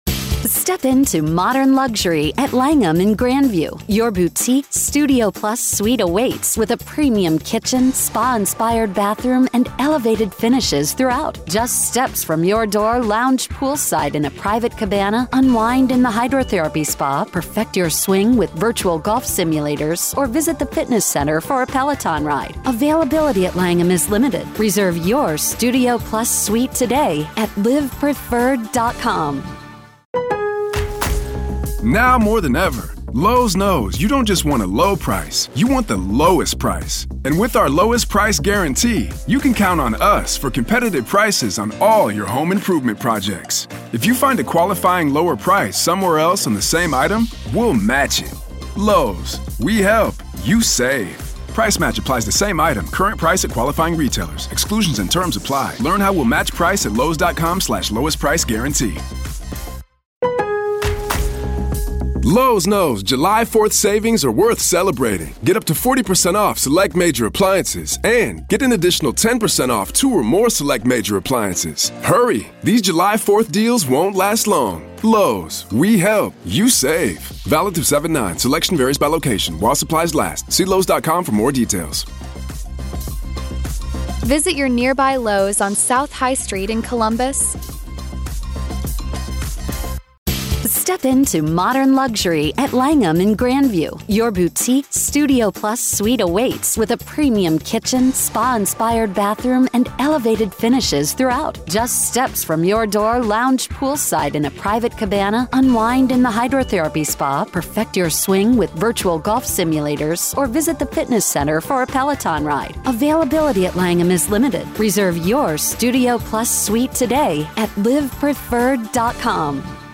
True Crime News & Commentary